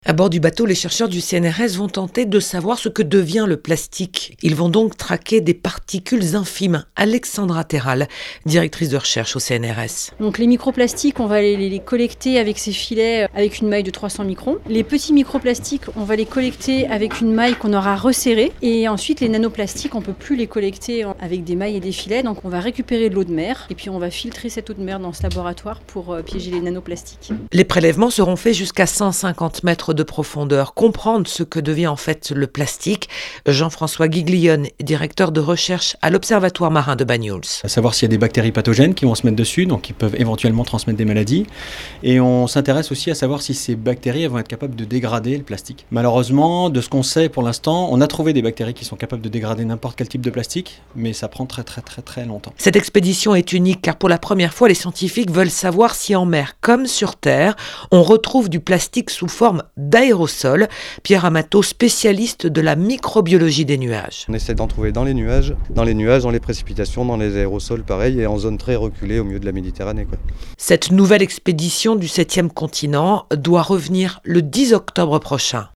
Reportage Sud Radio